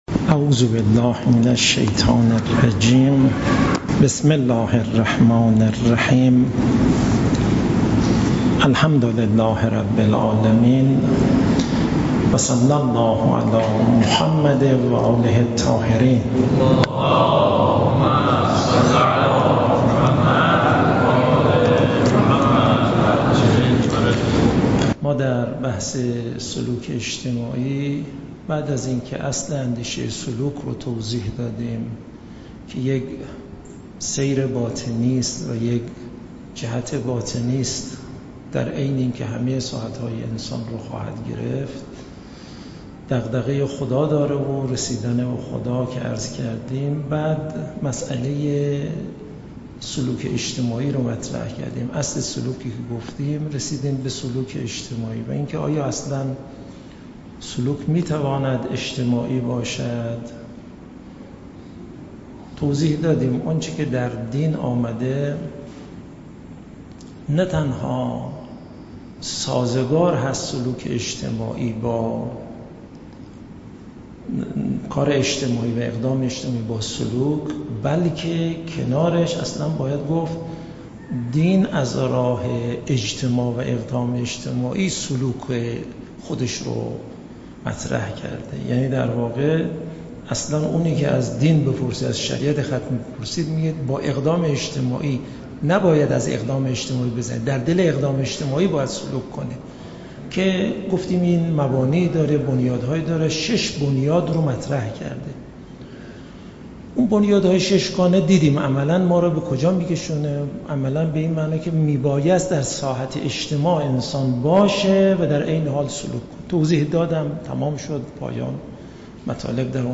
سخنرانی آیت‌الله یزدان پناه در ادامه بحث «سلوک اجتماعی» و پس از تبیین شش بنیاد آن، بر روی راهکارها و جزئیات تحقق سلوک در ساحت اجتماع تمرکز دارد.